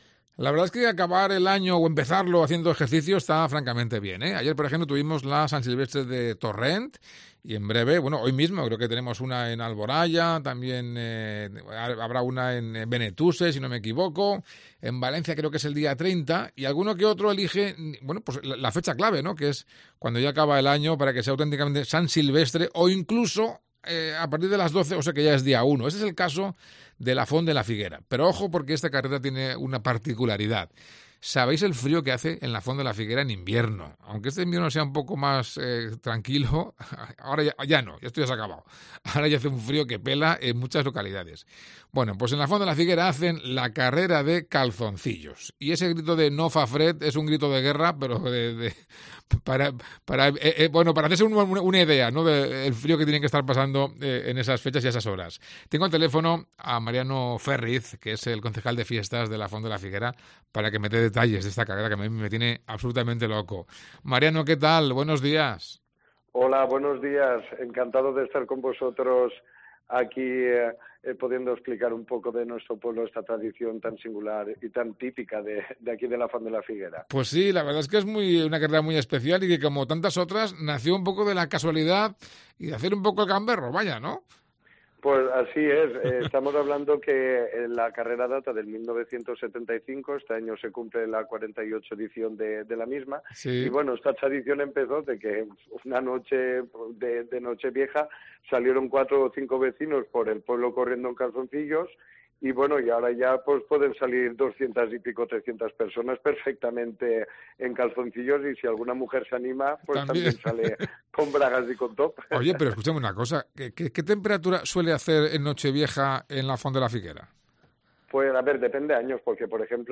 Mariano Férriz, concejal de Fiestas, explica en COPE los detalles de la peculiar carrera